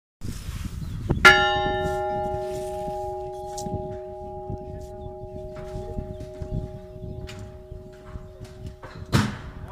cloche (n°1) - Inventaire Général du Patrimoine Culturel